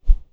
Close Combat Swing Sound 85.wav